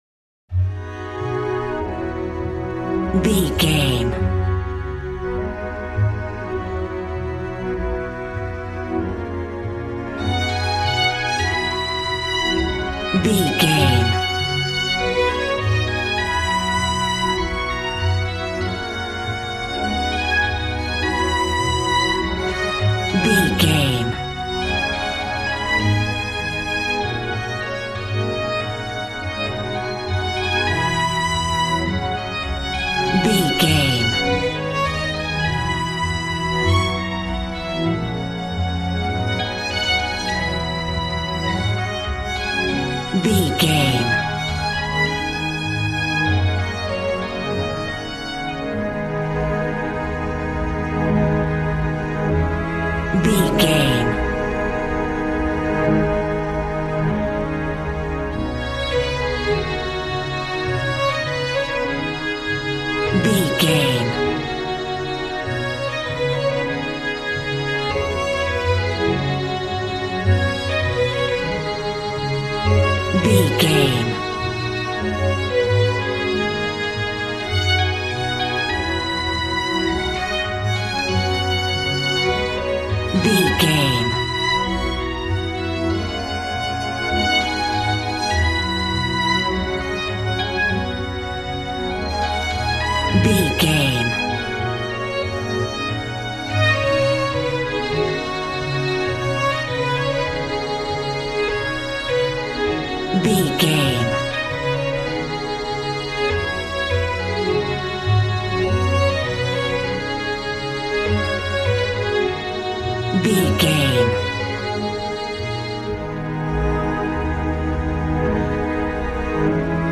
Aeolian/Minor
A♭
Fast
joyful
conga
80s